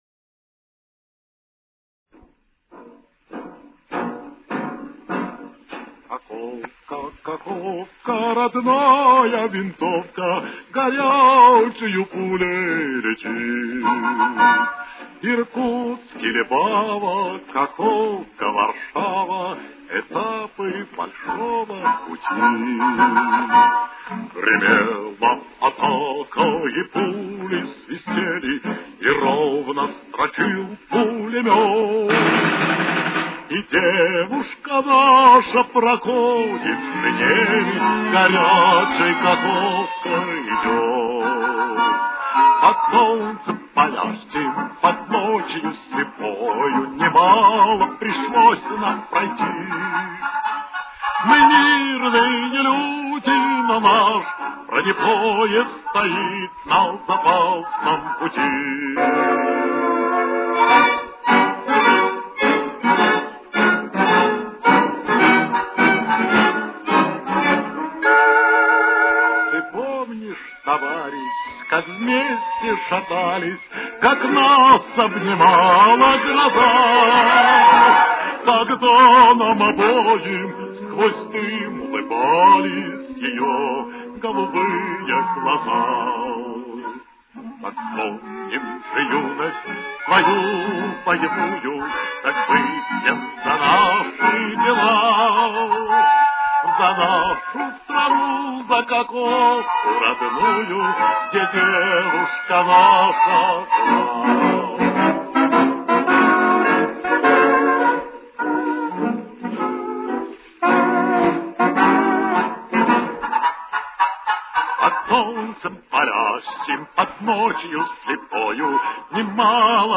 Темп: 110.